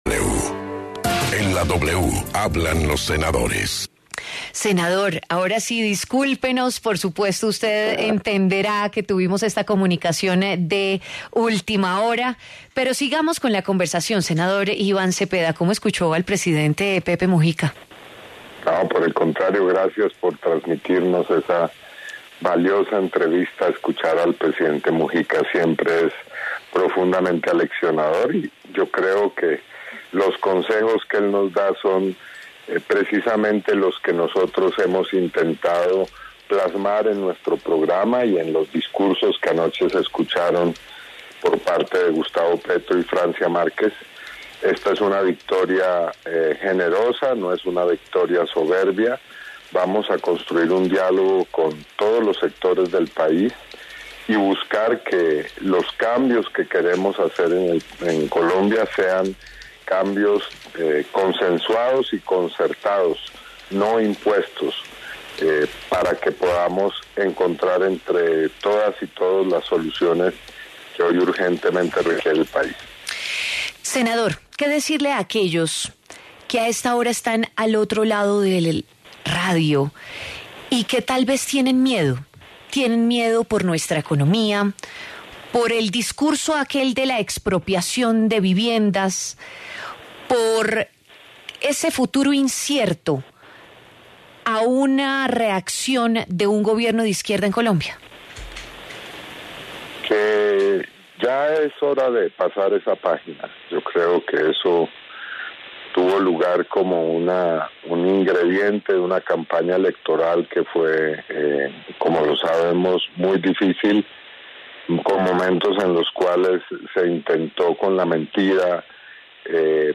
El senador Iván Cepeda pasó por los micrófonos de La W para hablar sobre la victoria de Gustavo Petro y Francia Márquez en las elecciones presidenciales.